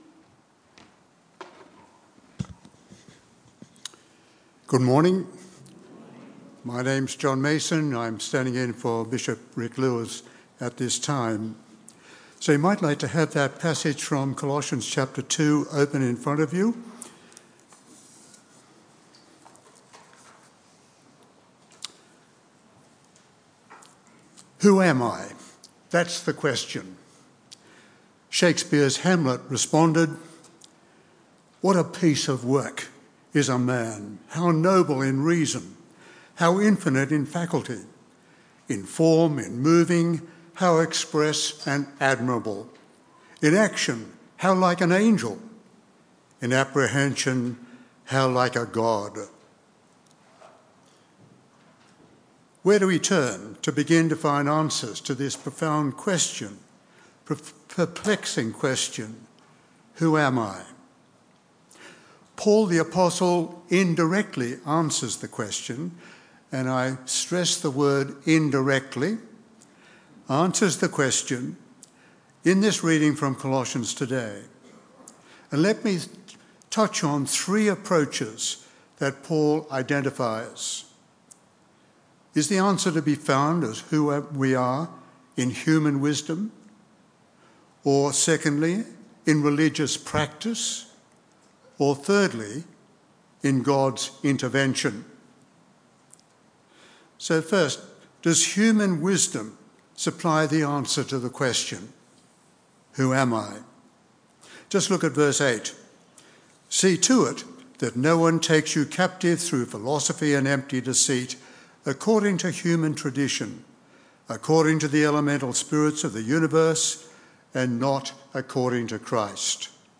A message from the series "Christ Our Hope."